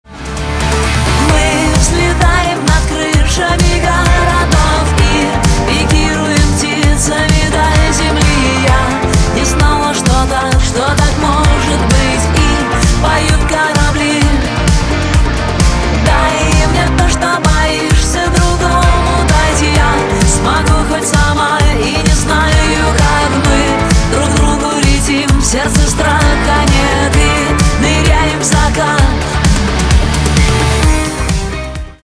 русский рок , гитара , барабаны
романтические